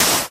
Minecraft / dig / sand1.ogg
sand1.ogg